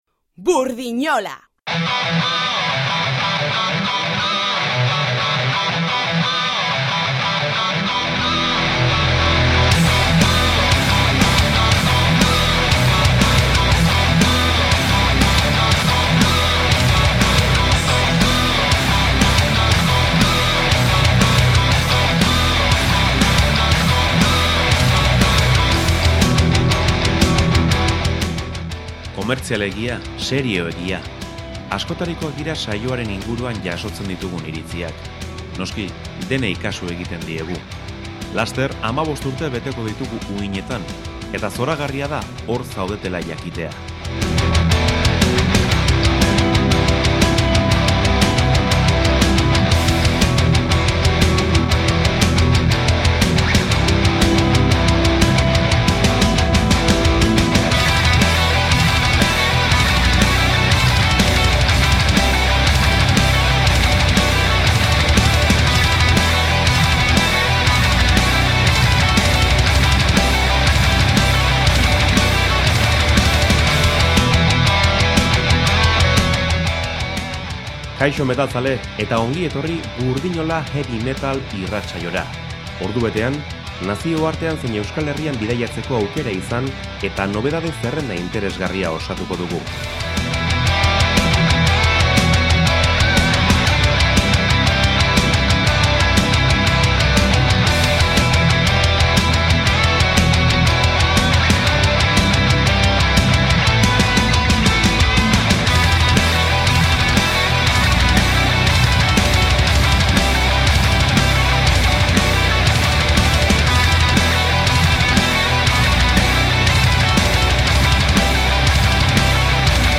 heavy metal irratsaioa